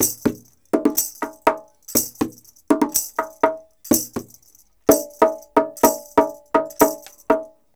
124-PERC4.wav